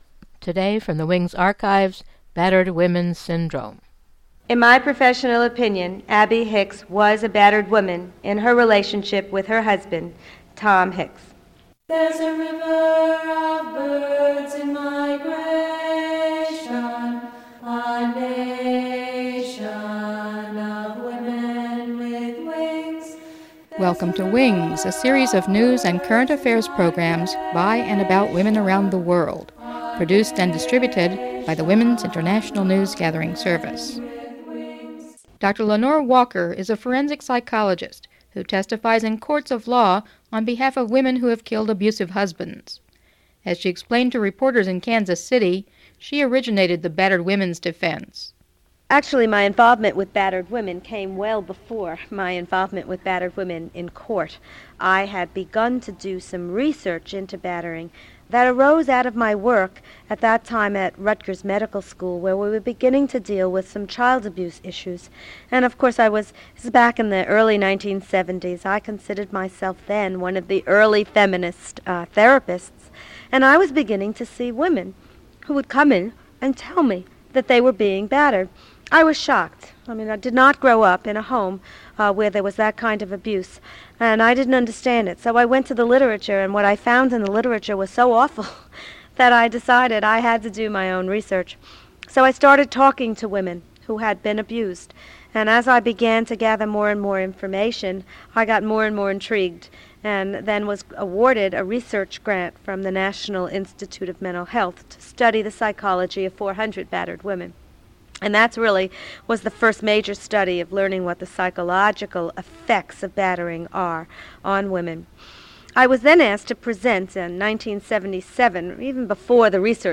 File Information Listen (h:mm:ss) 0:28:46 WINGS02-15BatteredWoman-28_46-192kbps.mp3 Download (37) WINGS02-15BatteredWoman-28_46-192kbps.mp3 41,433k 192kbps Mono Comments: Original was edited on open reel.